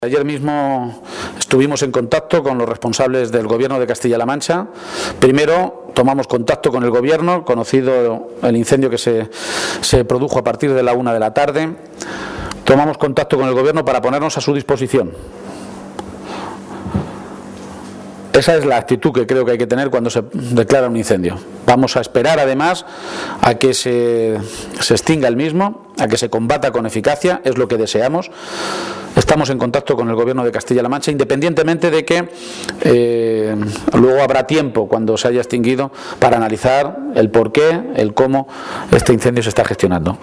García-Page ha realizado estas declaraciones en Puertollano, localidad en la que esta tarde tiene previsto reunirse con colectivos y sindicatos para analizar la tremenda situación por la que está atravesando la ciudad industrial.